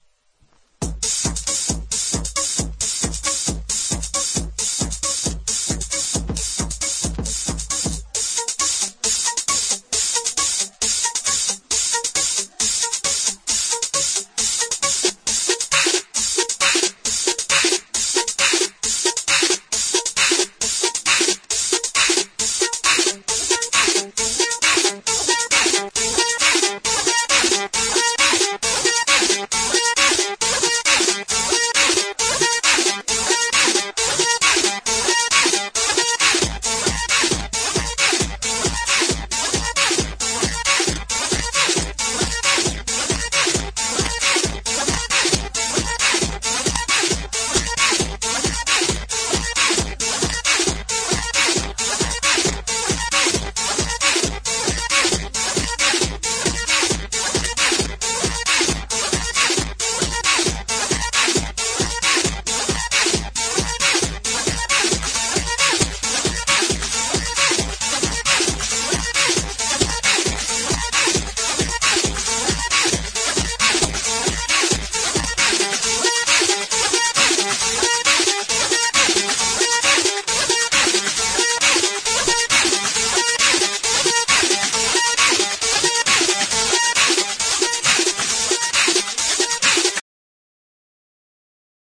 タイトル通り、マニアックなアシッド系テクノ。